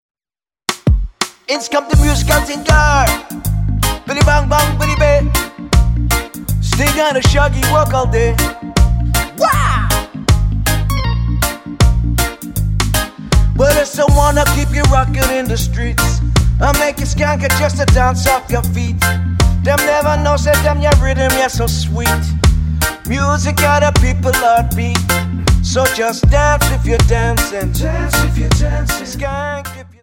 Tonart:Bb Multifile (kein Sofortdownload.
Die besten Playbacks Instrumentals und Karaoke Versionen .